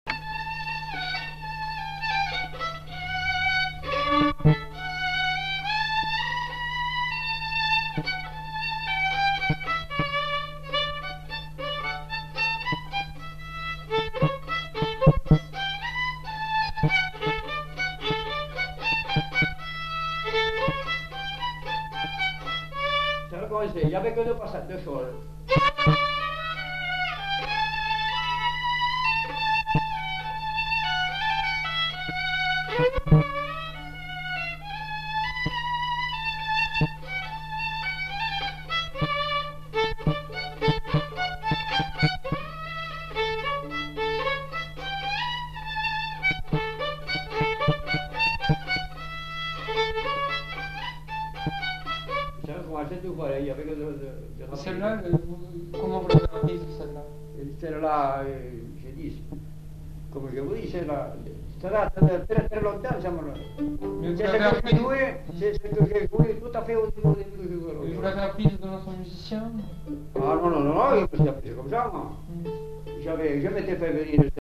Valse
Lieu : Casteljaloux
Genre : morceau instrumental
Instrument de musique : violon
Danse : valse